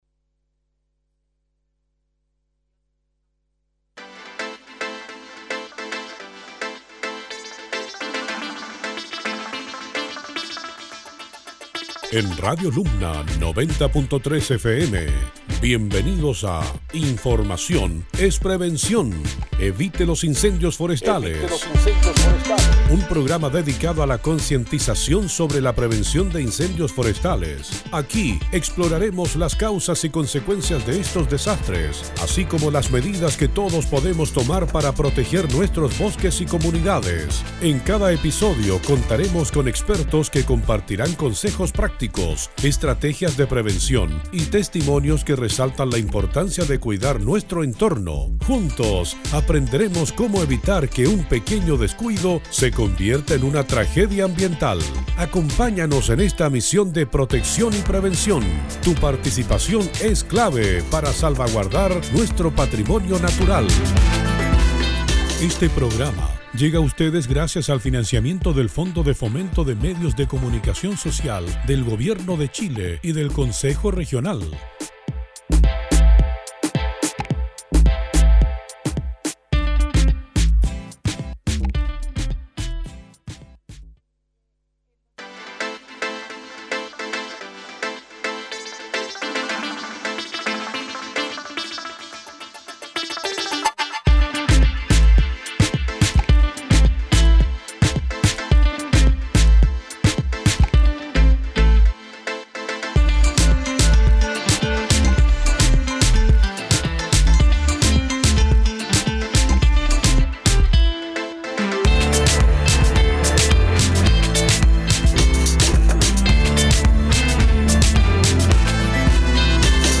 Durante este capítulo tuvimos la oportunidad de entrevistar